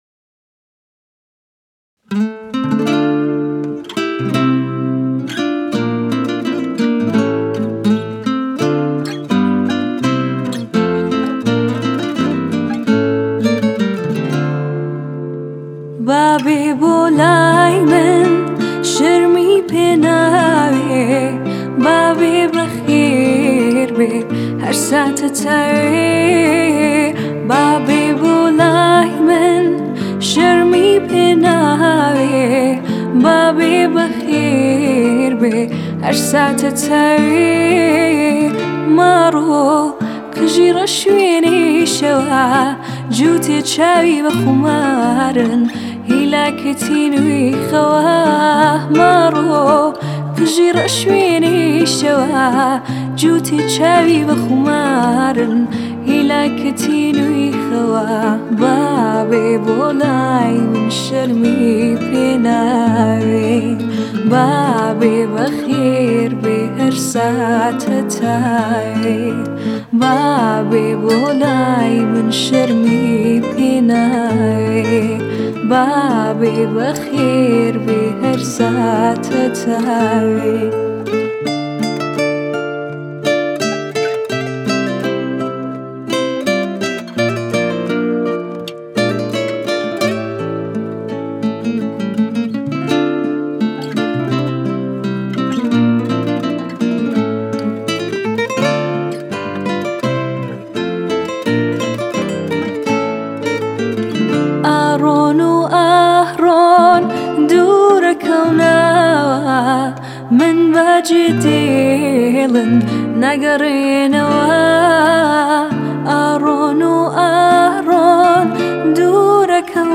آهنگ کردی جدید